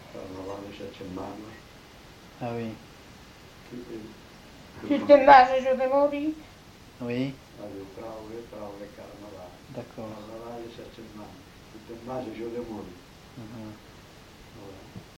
Genre : chant
Effectif : 1
Type de voix : voix d'homme
Production du son : récité
Classification : chanson de carnaval